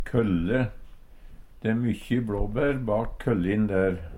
kølle - Numedalsmål (en-US)